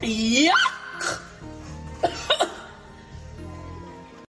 yyyuckkkkk Meme Sound Effect
yyyuckkkkk.mp3